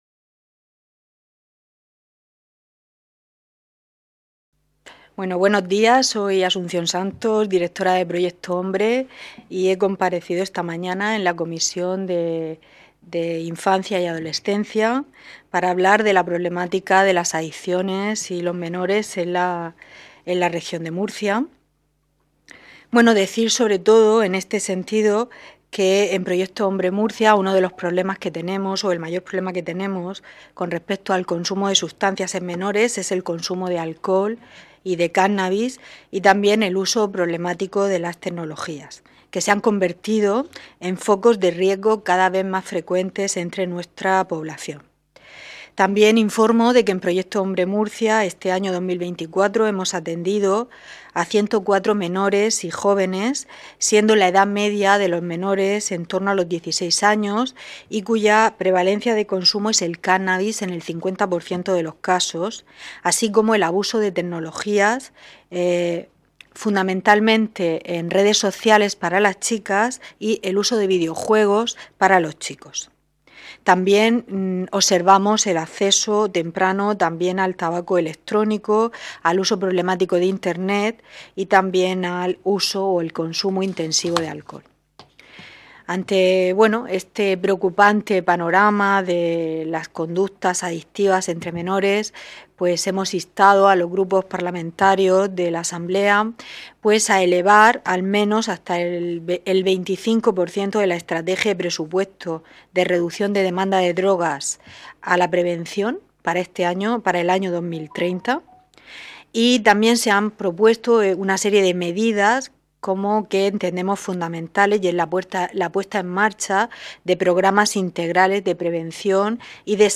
Rueda de prensa posterior a la Comisión Especial de Estudio sobre Infancia y Adolescencia